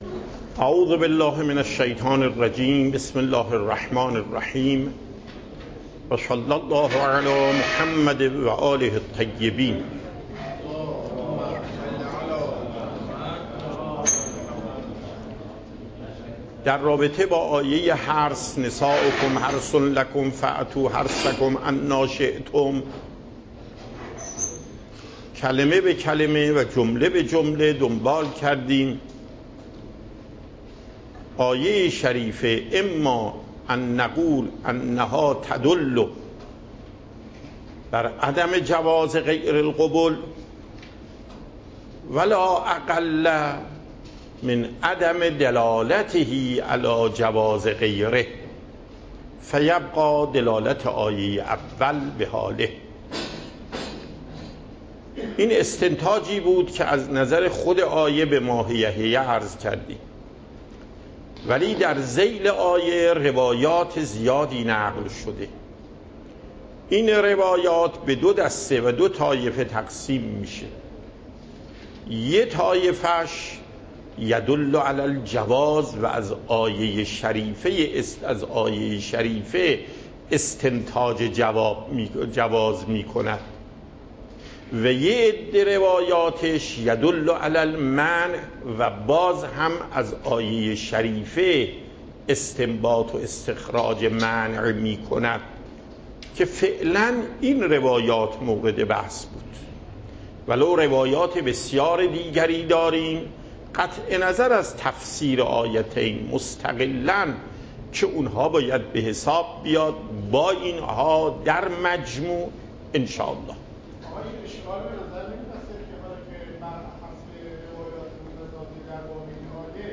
دروس فقه آیت الله محقق داماد